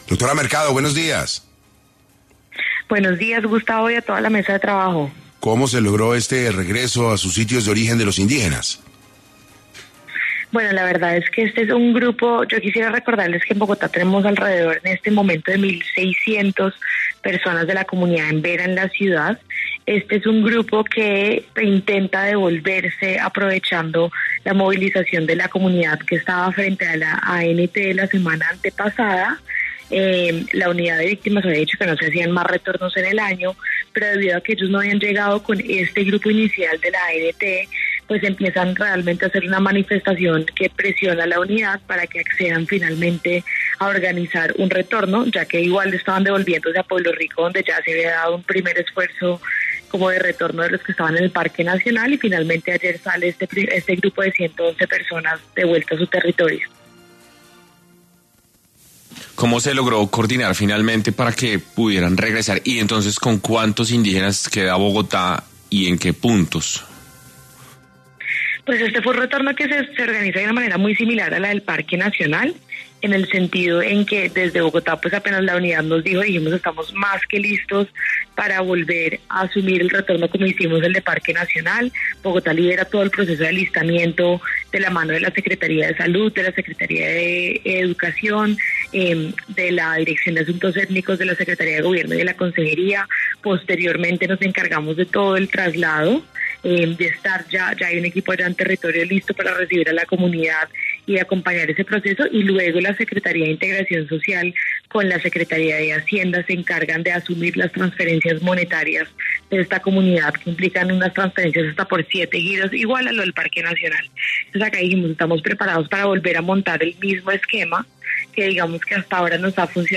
En 6AM de Caracol Radio estuvo Isabelita Mercado, nueva Alta Consejera para las Víctimas, Paz y Reconciliación, quien habló sobre cómo avanza el retorno de los Emberá a sus territorios y cuántos quedarán en Bogotá.